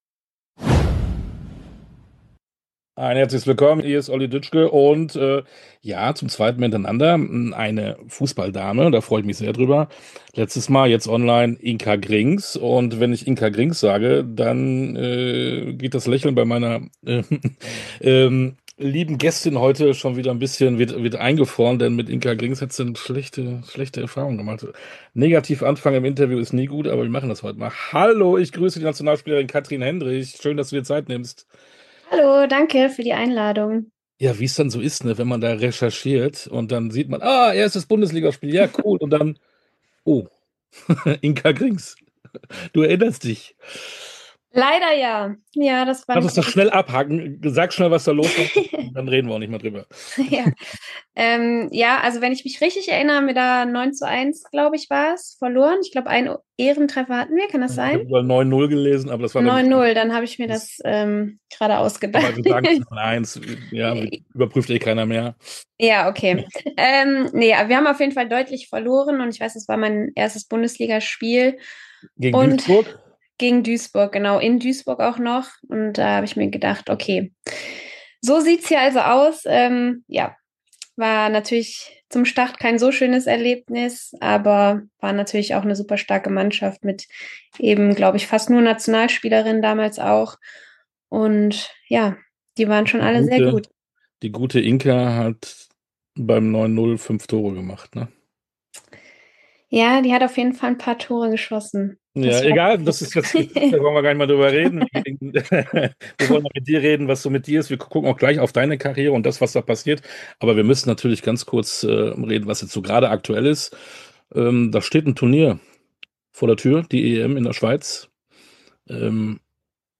Sportstunde - Interview komplett Kathrin Hendrich, Fussball Nationalspielerin ~ Sportstunde - Interviews in voller Länge Podcast
Tauchen Sie ab in die Sportstunde ungeungeschnittentinterviews in ihrer authentischen, ungeschnittenen Langfassung.
Interview_Kathrin_Hendrich-_Fussball_-_Nationalspielerin_(1).mp3